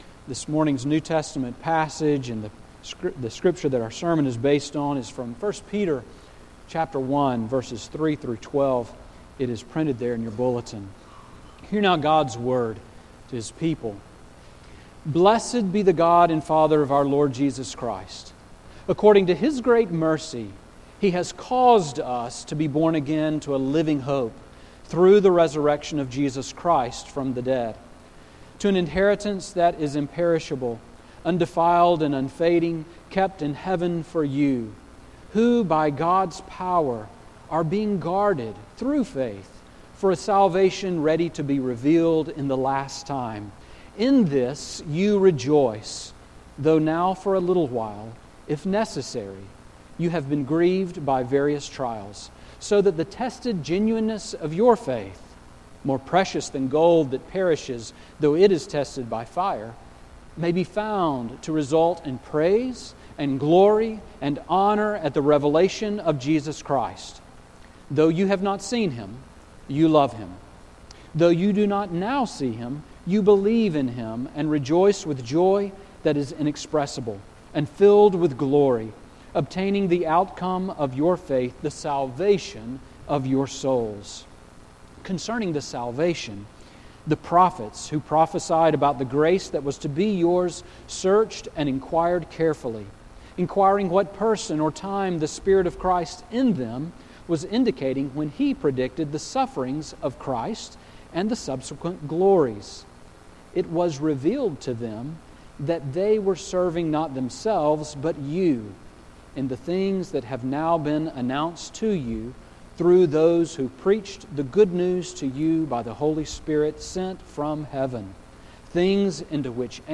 Sermon on 1 Peter 1:3-12 from January 10